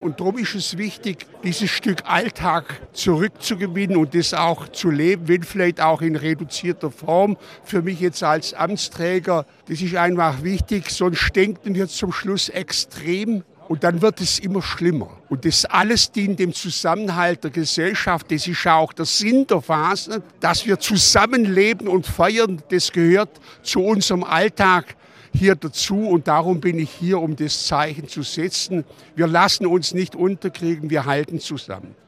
Kretschmann betonte aber im Gespräch mit dem SWR, wie wichtig die Fastnacht für den Zusammenhalt der Gesellschaft sei.
Ministerpräsident Winfried Kretschmann (Grüne)